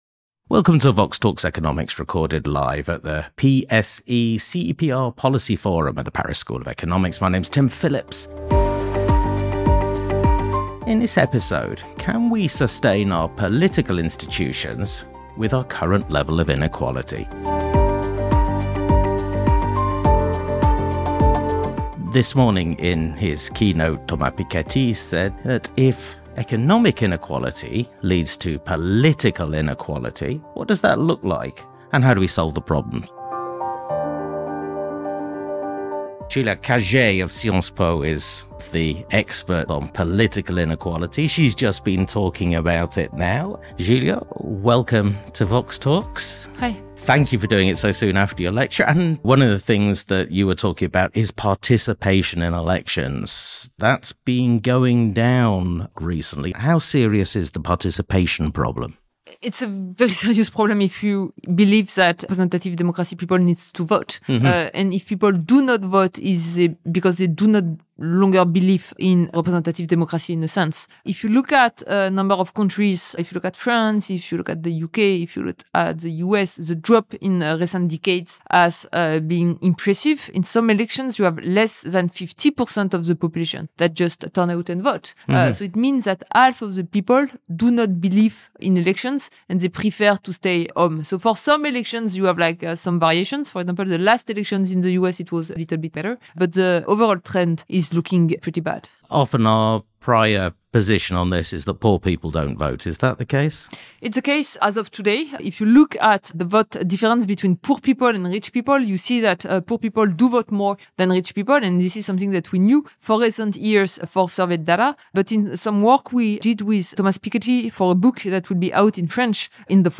Recorded live at the PSE-CEPR Policy Forum at the Paris School of Economics. Does economic inequality create political inequality?